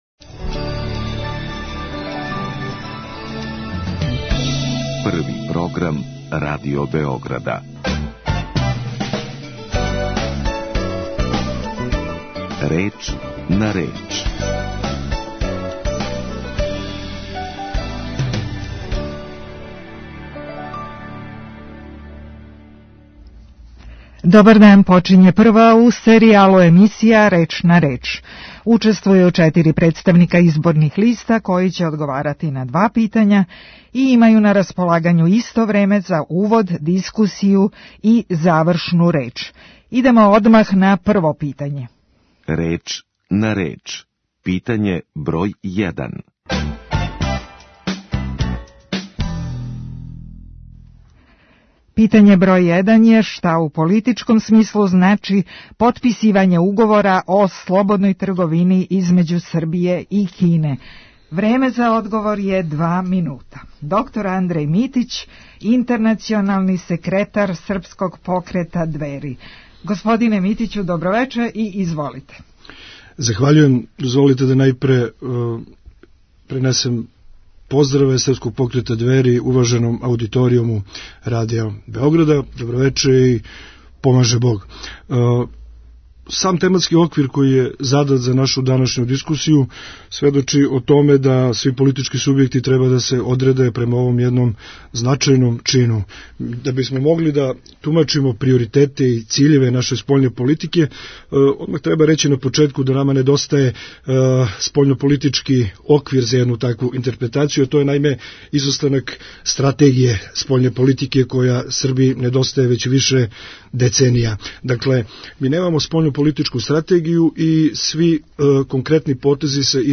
Сваког петка од 17.05. учествоваће четири представника изборних листа које најављују учешће на изборима 3.априла. Разговор у емисији одвија се према унапред одређеним правилима тако да учесници имају на располагању исто време за увод, дискусију и завршну реч.